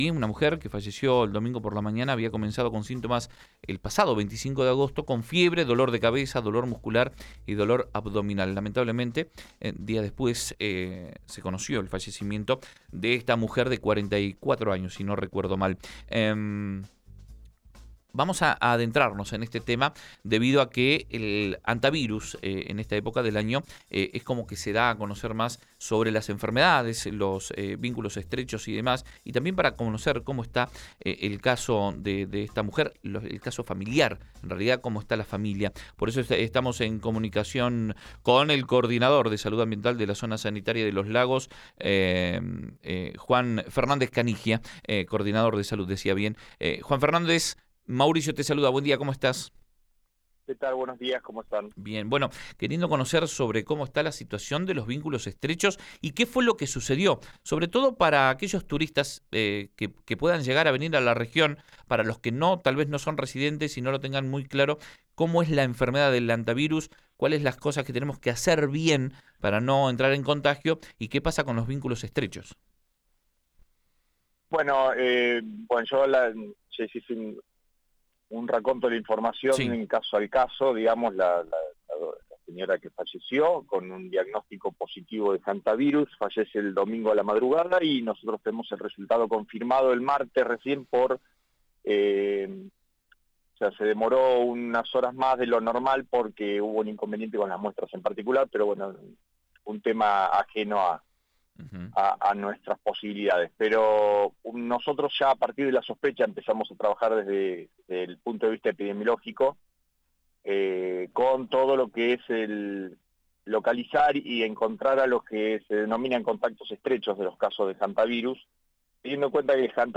Señaló en diálogo con Panorama Informativo que a nivel epidemiológico inmediatamente se empezó a trabajar con los contactos estrechos.